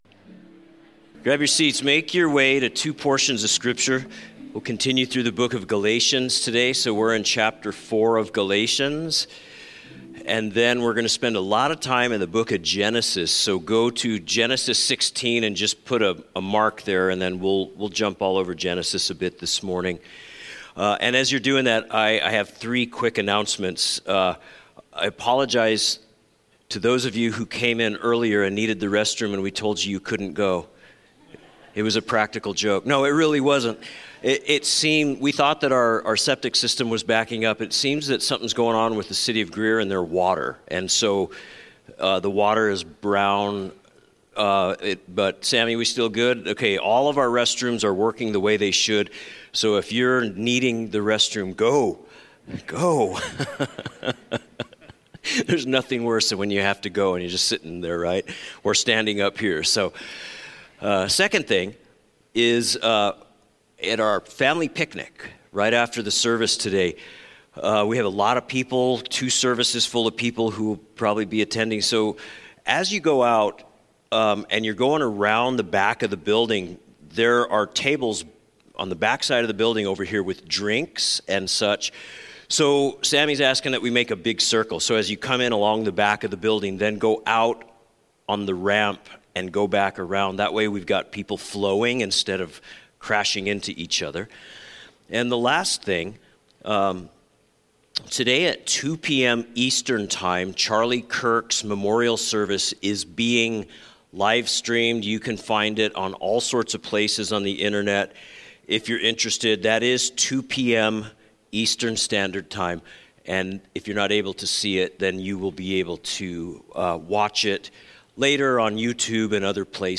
A message from the series "Sunday Morning."